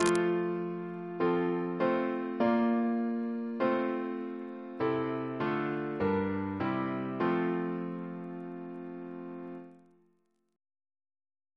Single chant in F Composer: Henry G. Ley (1887-1962) Reference psalters: ACB: 105